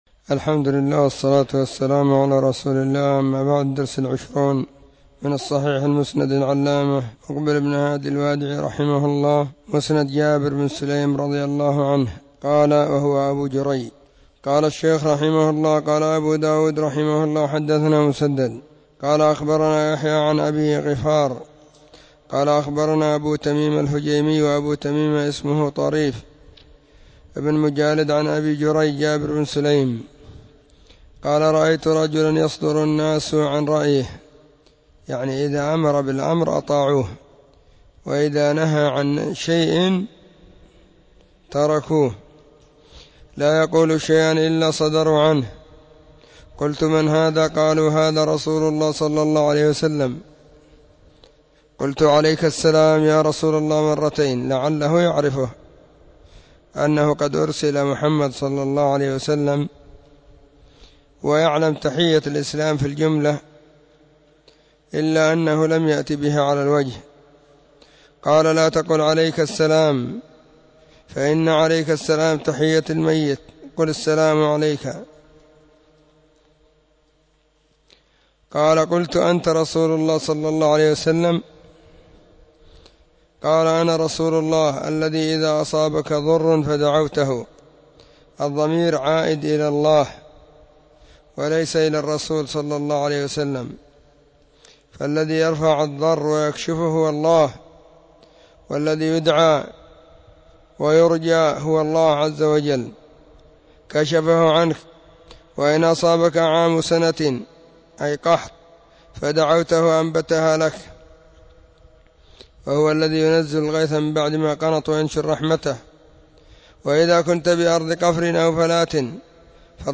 الصحيح_المسند_مما_ليس_في_الصحيحين_الدرس_20.mp3